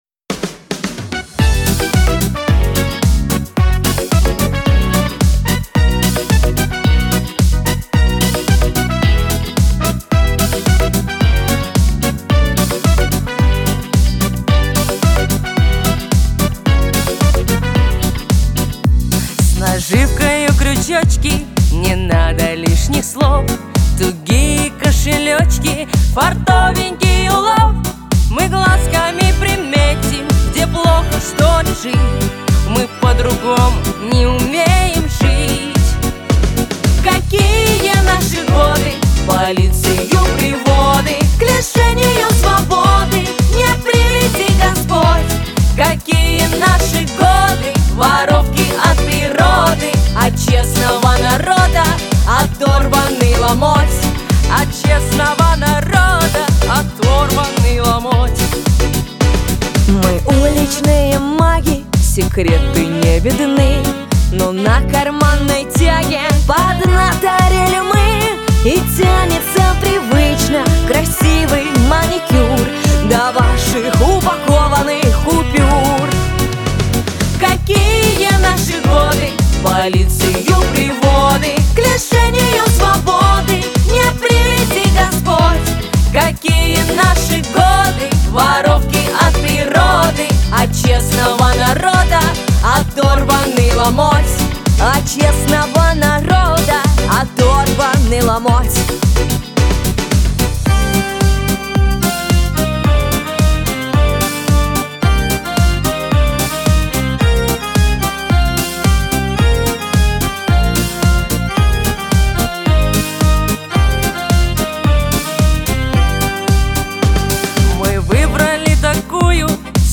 эстрада , дуэт , Шансон
Лирика